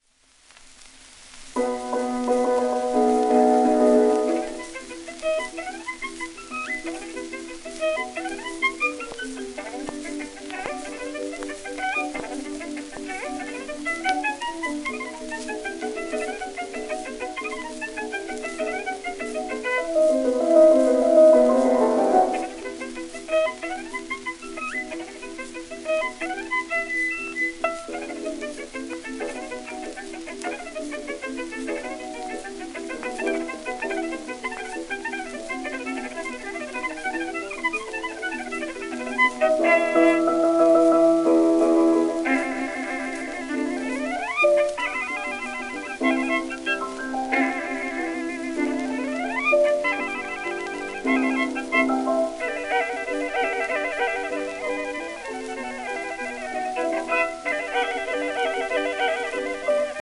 1917年録音
旧 旧吹込みの略、電気録音以前の機械式録音盤（ラッパ吹込み）